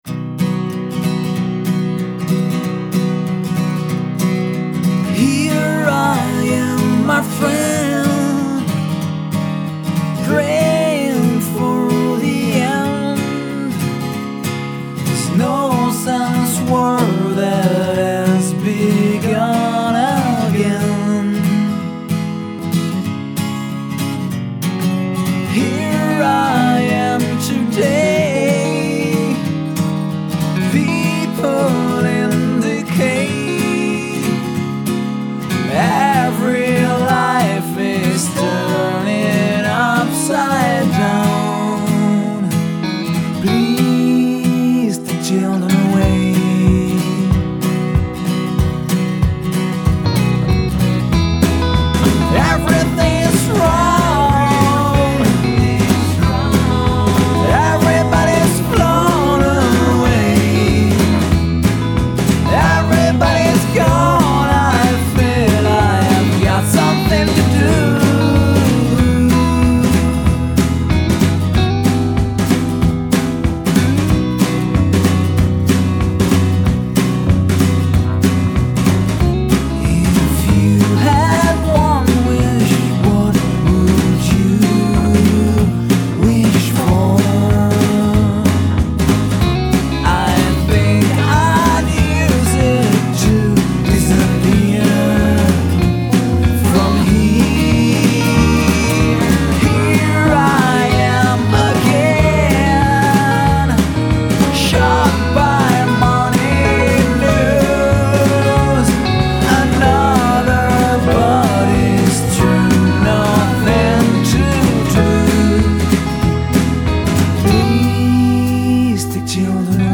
a Beatles influence revised through a folk sensibility